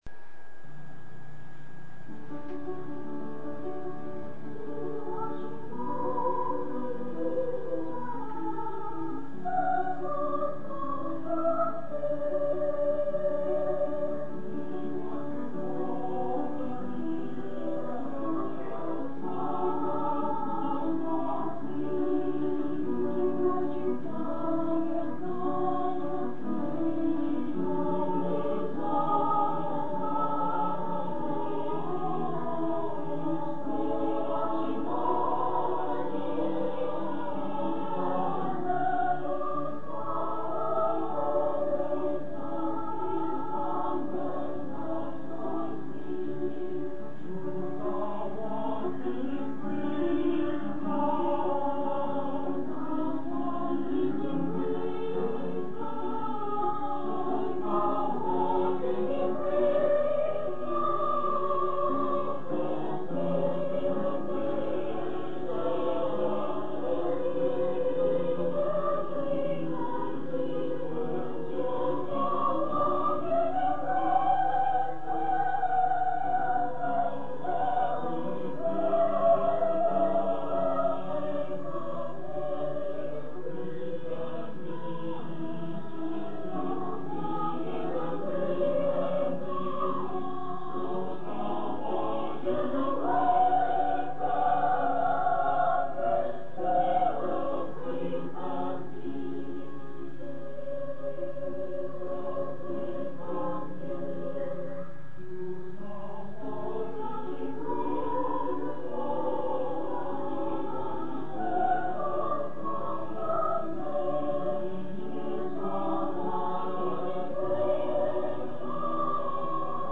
1969 Choir Spring Concert